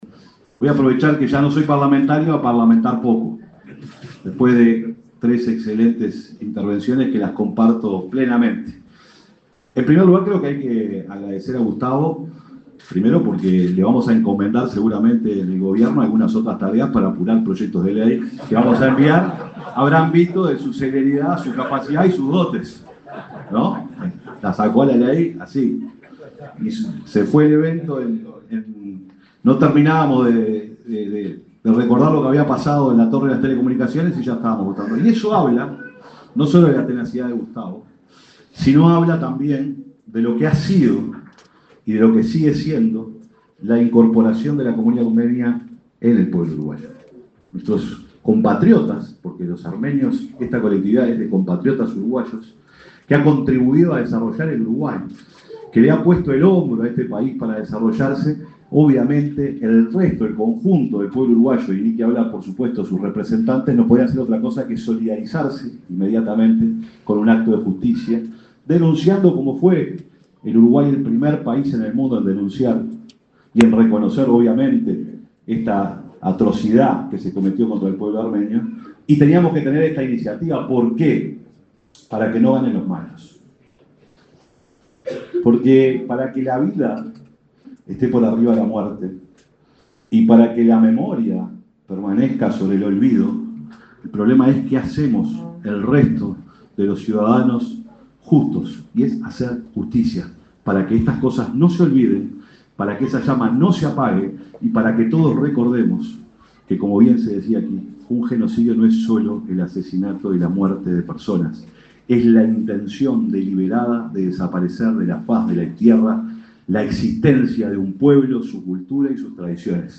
Palabras del secretario de Presidencia, Alejandro Sánchez
En el marco de la conmemoración de los 110 años del Genocidio Armenio, se expresó el secretario de Presidencia, Alejandro Sánchez.